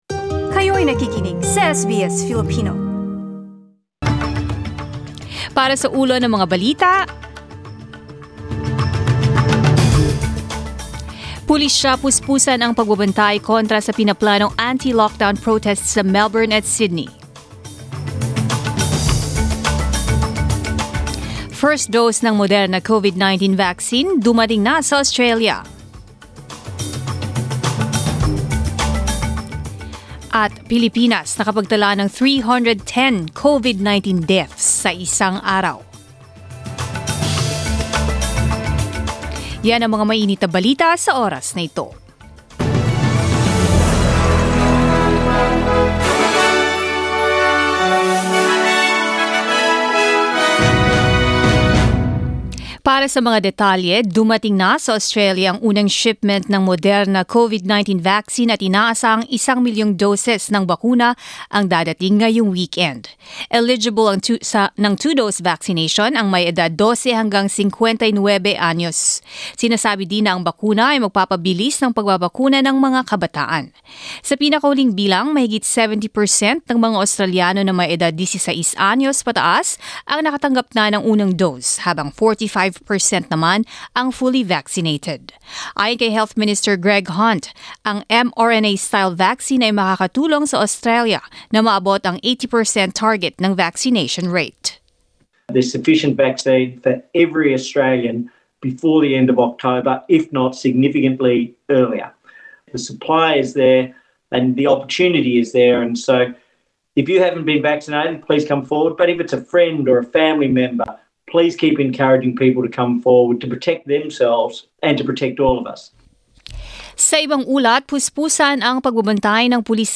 SBS News in Filipino, Saturday 18 September